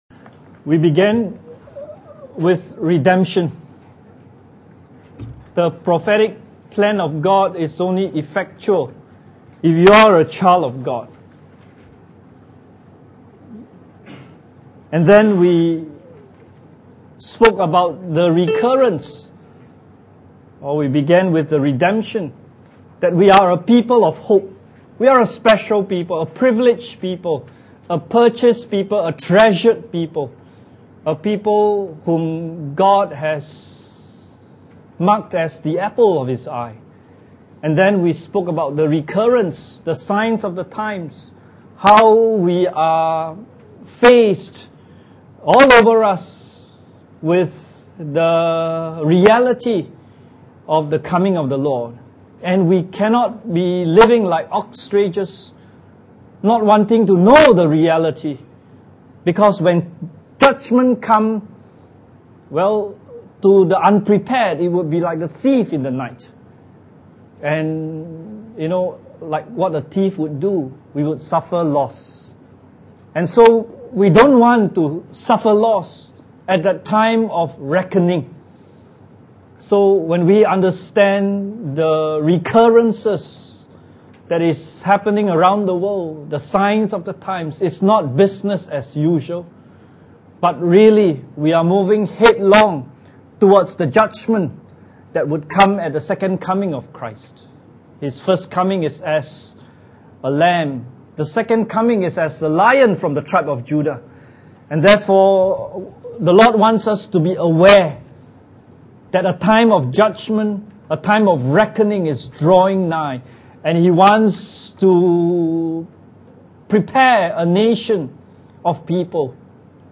Church Camp 2015 Looking for That Blessed Hope – Rule (Millennium) Message 6